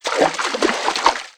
High Quality Footsteps / Water / Misc
MISC Water, Splash 05.wav